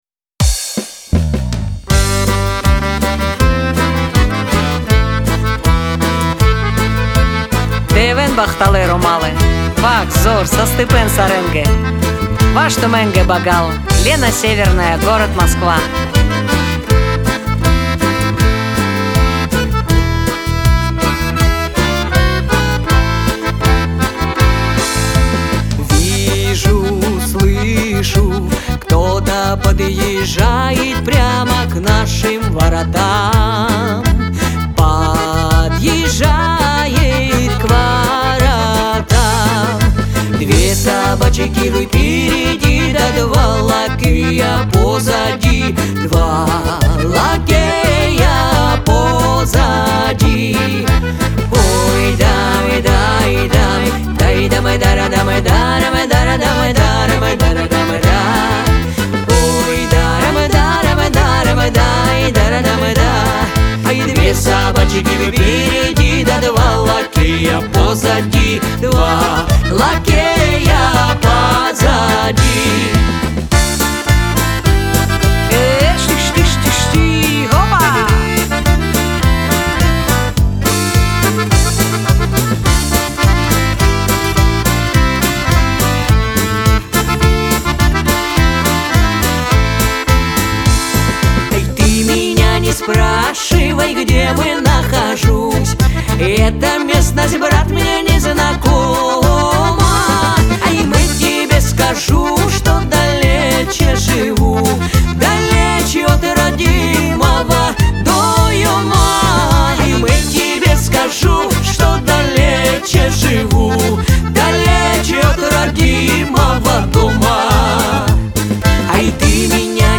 Веселая музыка
диско , танцевальная музыка